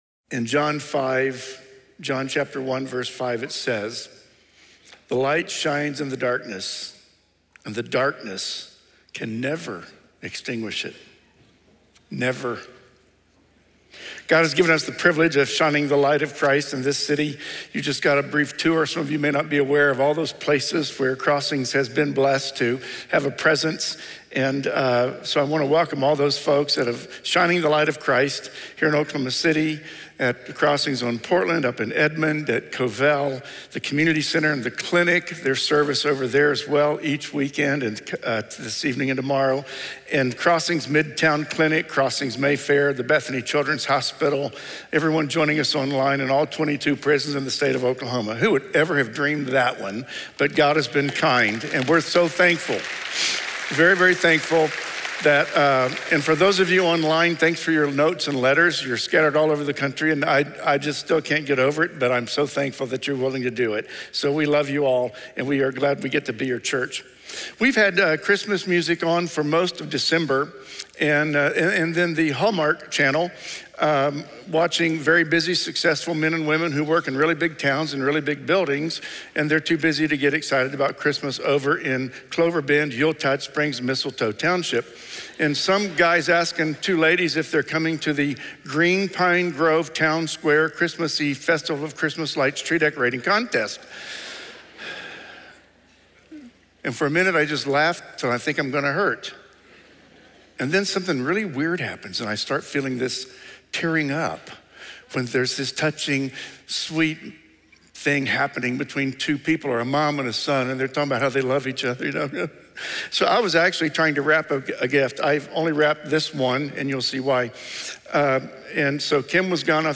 Come and See (2024 Candlelight Service)